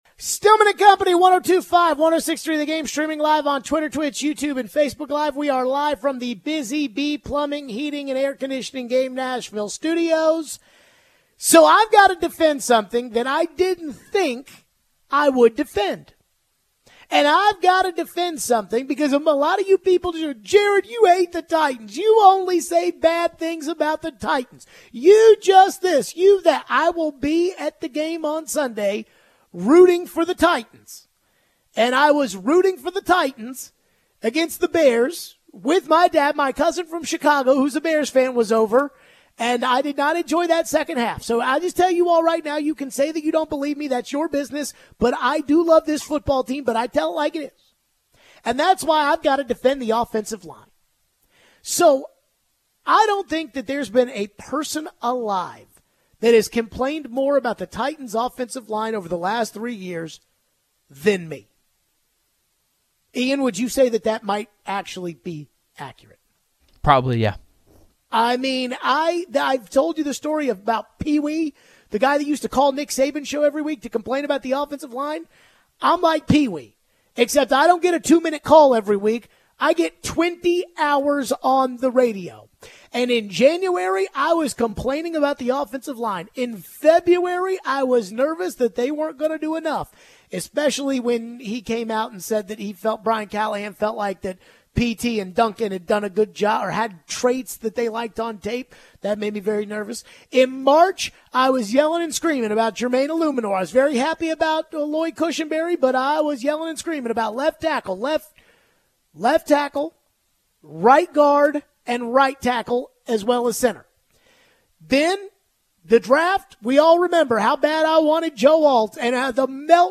Taking your calls and texts.